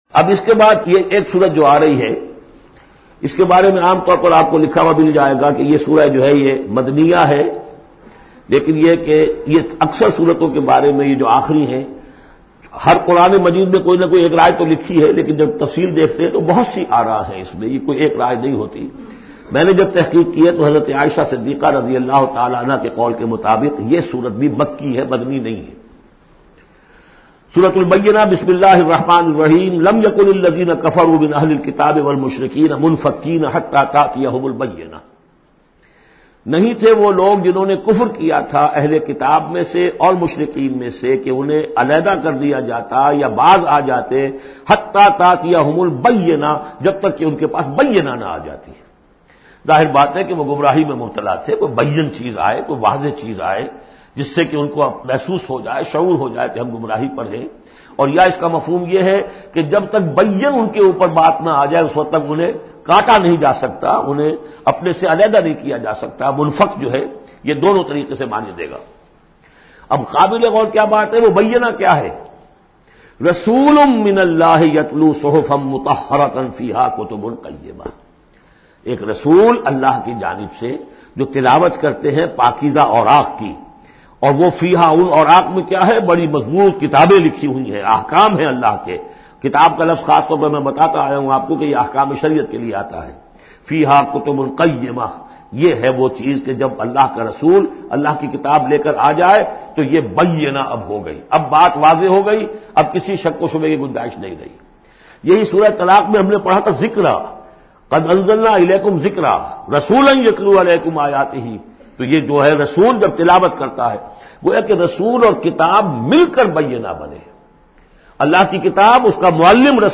Surah Bayyinah is 98th chapter of Holy Quran. Listen online mp3 tafseer of Surah Bayyinah in the voice of Dr Israr Ahmed.